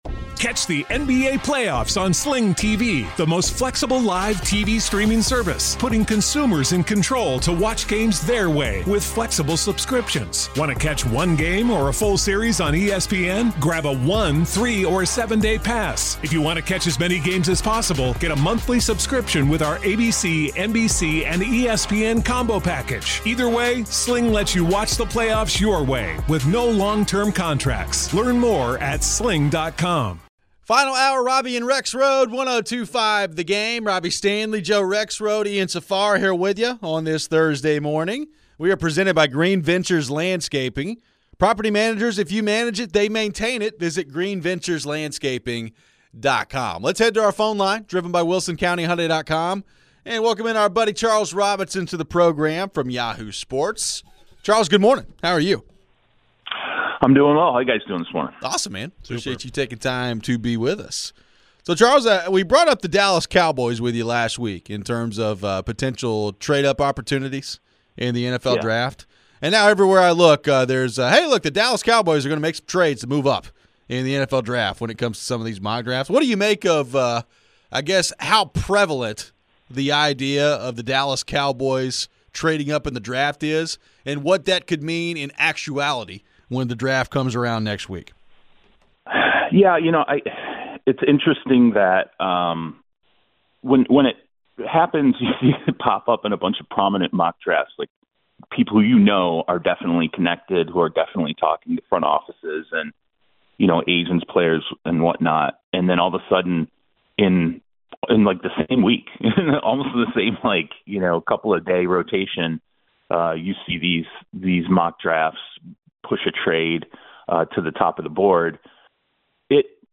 We close out the show with your final phones.